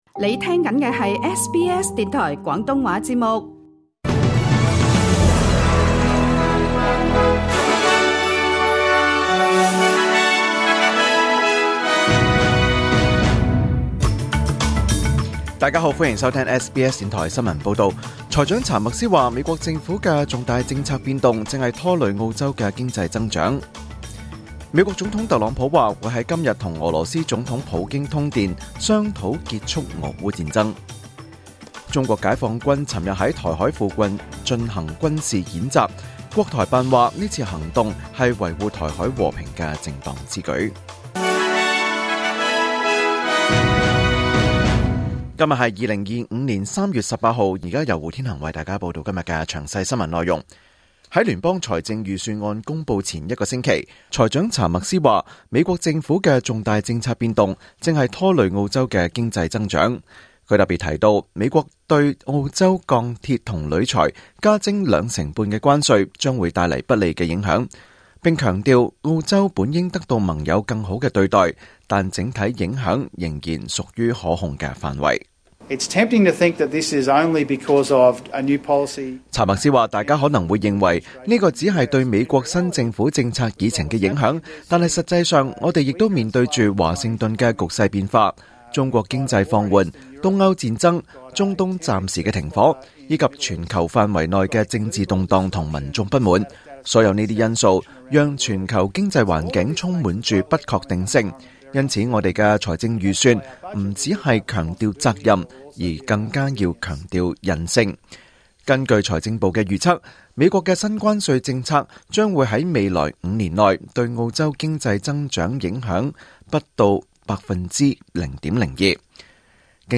2025 年 3 月 18 日 SBS 廣東話節目詳盡早晨新聞報道。